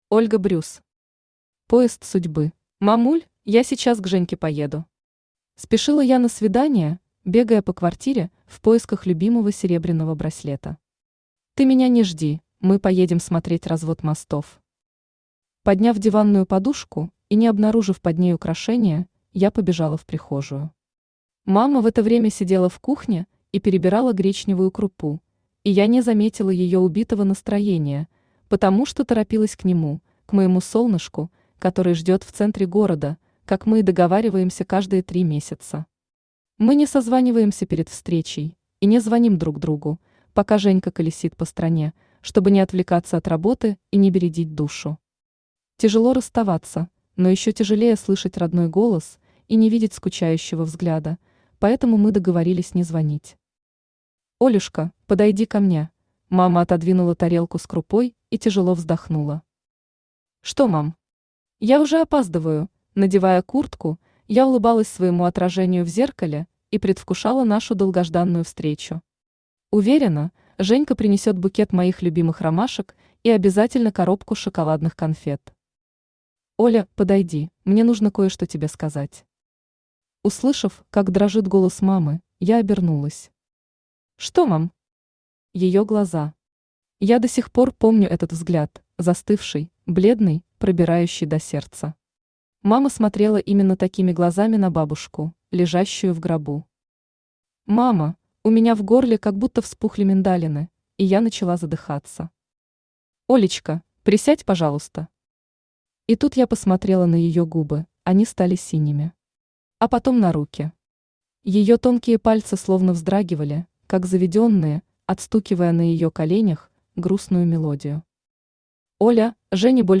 Аудиокнига Поезд судьбы | Библиотека аудиокниг
Aудиокнига Поезд судьбы Автор Ольга Брюс Читает аудиокнигу Авточтец ЛитРес.